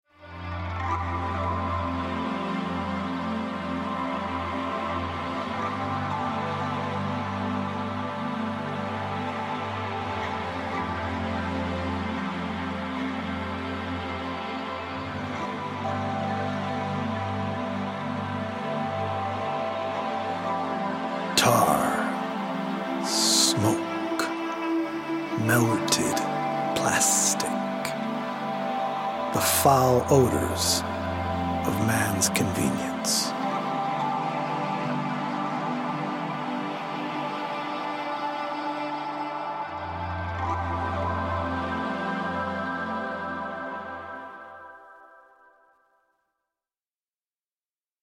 healing audio-visual poetic journey
healing Solfeggio frequency music
EDM producer